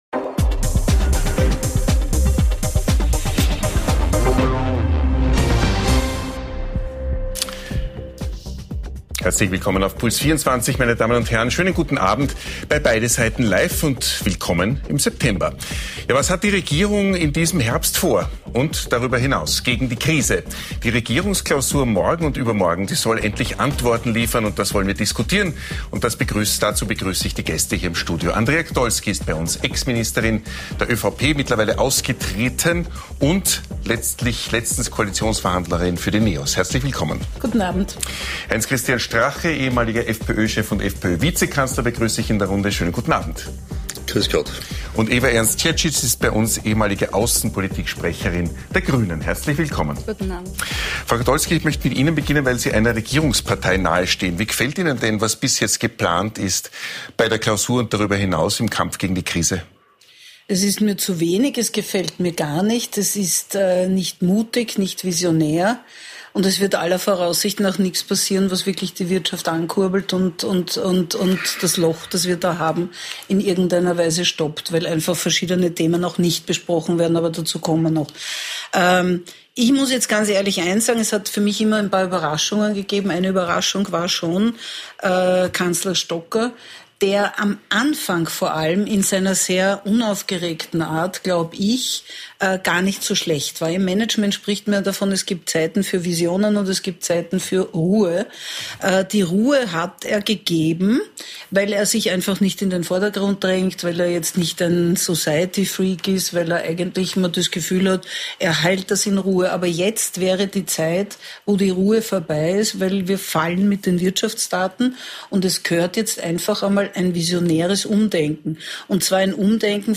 Zu Gast: H.C. Strache, Ewa Ernst-Dziedzic & Andrea Kdolsky ~ Beide Seiten Live Podcast